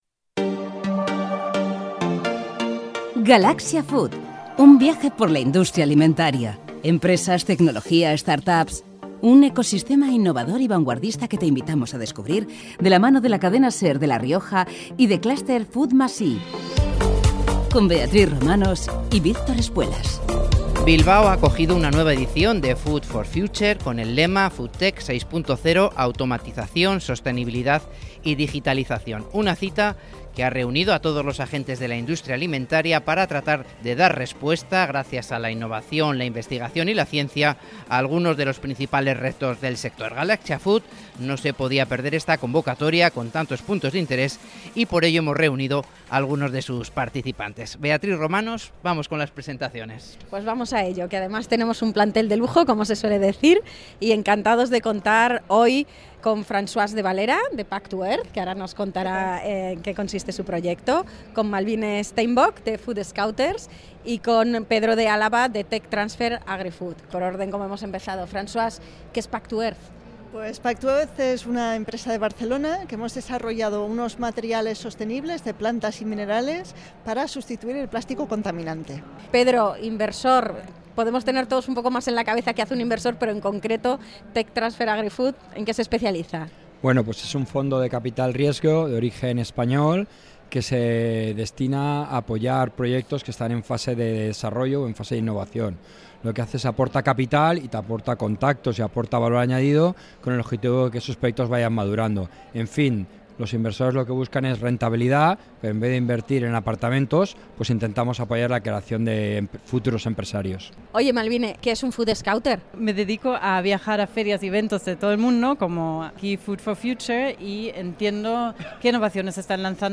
El podcast del Clúster FOOD+i y la Cadena SER reúne en este capítulo a un inversor, una startup y una consultora de nuevas tendencias, para analizar los avances en el ecosistema FoodTech en el marco de una nueva edición de la feria Food 4 Future – Expo Foodtech, que se ha celebrado en Bilbao bajo el lema Foodtech 6.0. Automation.